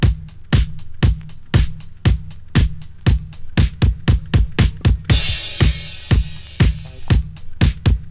• 118 Bpm High Quality Drum Beat E Key.wav
Free drum groove - kick tuned to the E note. Loudest frequency: 1150Hz
118-bpm-high-quality-drum-beat-e-key-TQV.wav